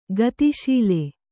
♪ gati śile